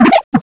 pokeemerald / sound / direct_sound_samples / cries / watchog.aif